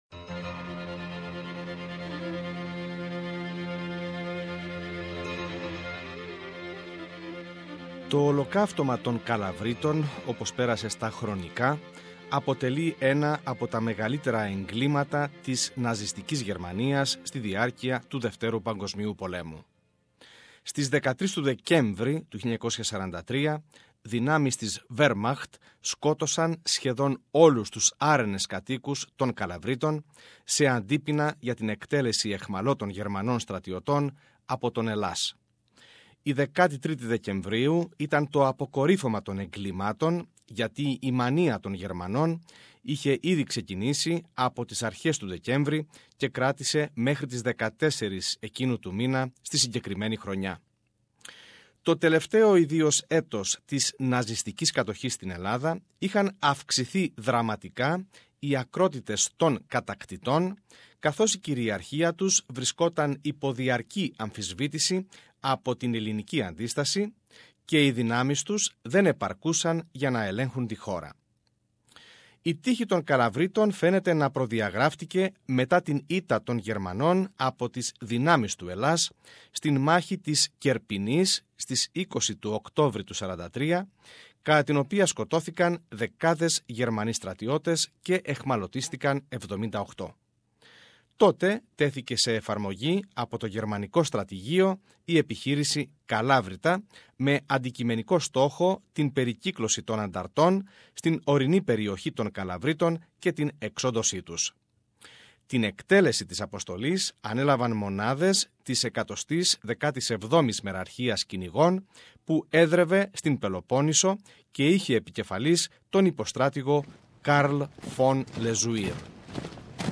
The tribute includes eyewitness accounts of Greek Australians who lived the atrocities.